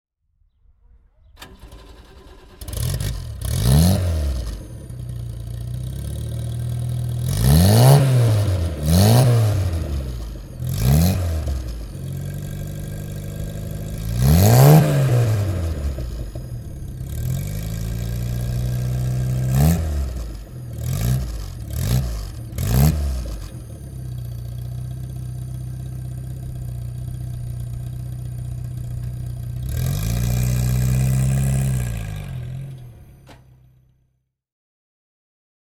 Renault_4_CV_1957.mp3